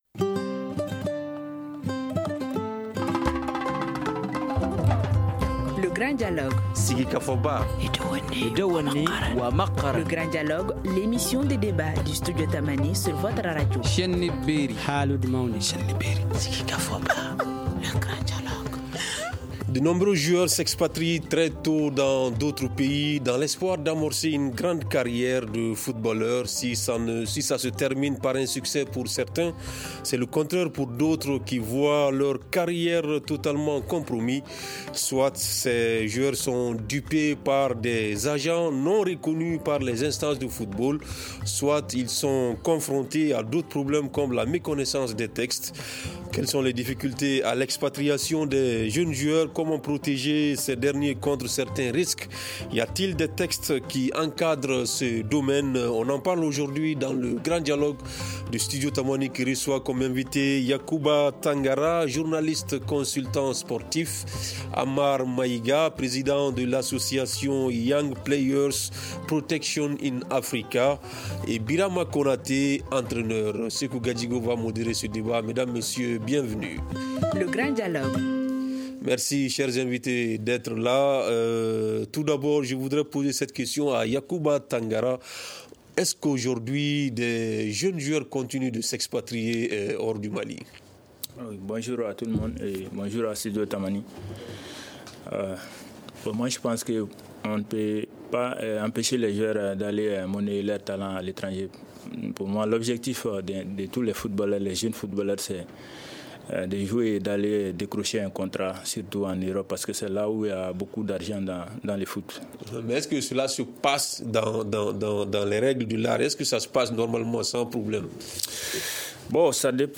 On en parle aujourd’hui dans le grand dialogue avec nos invités :